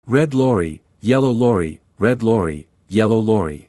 Tongue Twisters!